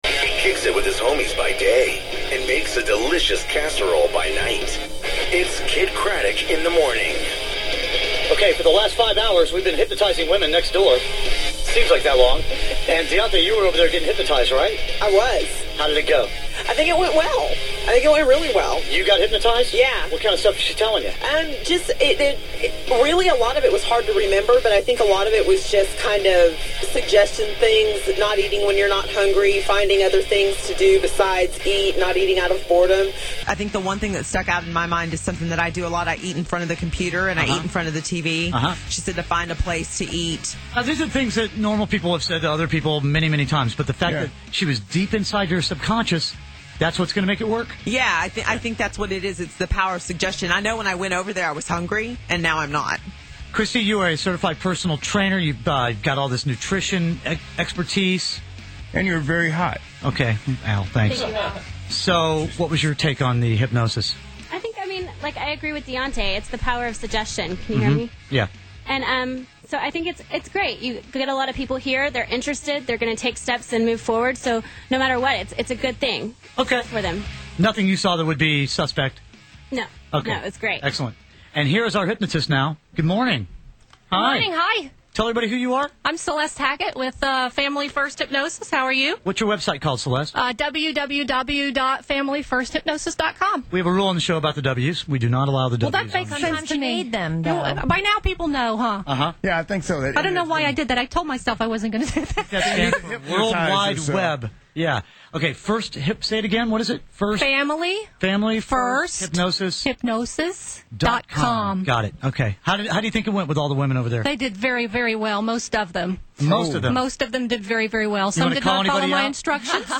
I’ve been featured on the nationally syndicated radio morning show “Kidd Kraddick in the Morning”.